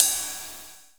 Wu-RZA-Hat 7.wav